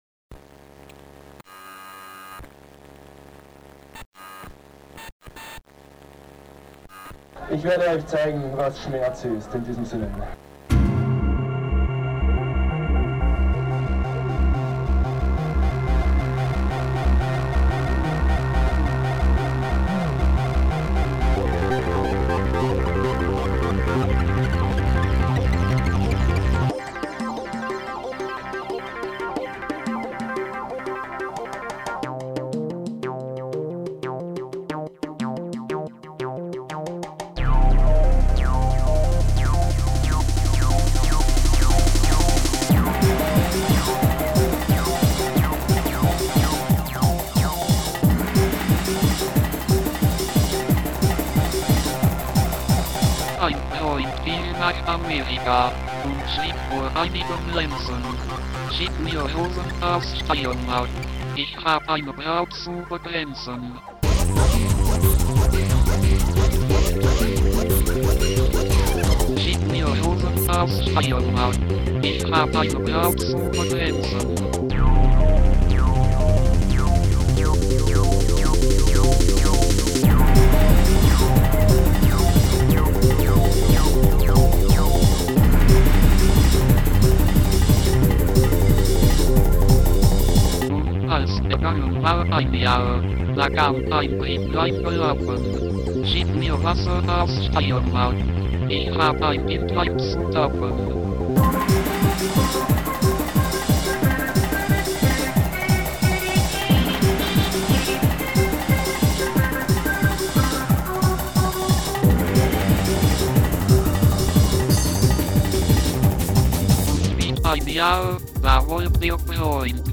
Hardcore-Techno-Coverversion
Hier ist die Techno-Version